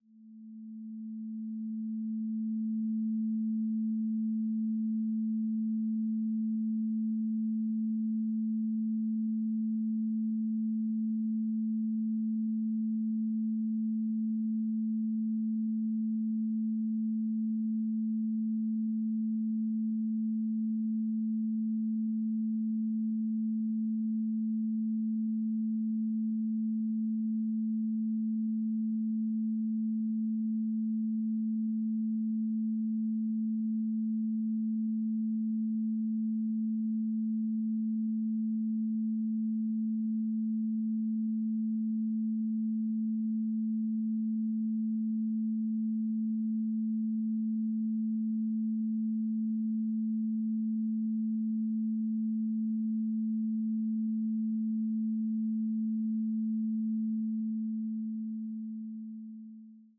Duration: 1:00 · Genre: Classical · 128kbps MP3